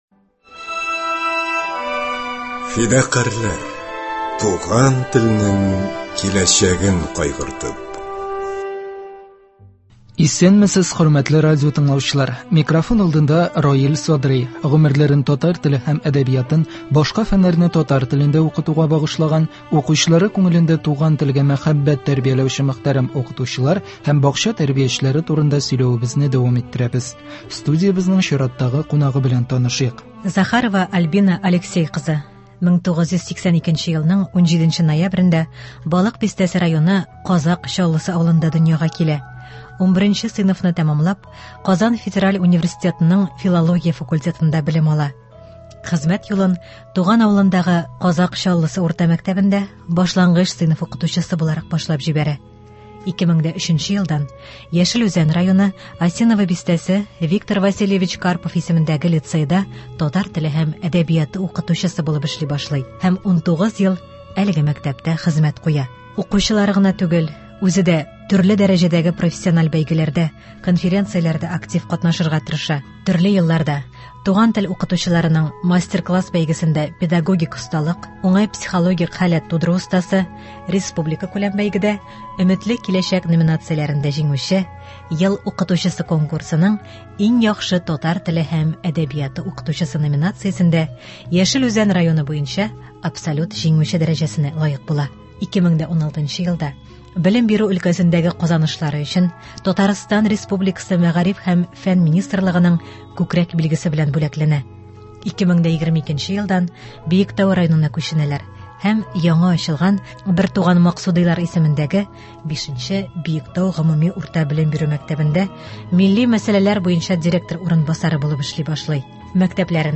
Гомерләрен татар теле һәм әдәбиятын, башка фәннәрне татар телендә укытуга багышлаган, укучылары күңелендә туган телгә мәхәббәт тәрбияләүче мөхтәрәм укытучылар һәм бакча тәрбиячеләре турында сөйләвебезне дәвам иттерәбез. Студиябезнең чираттагы кунагы